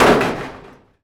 metal_med_impact_02.wav